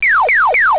gs_returnghost.au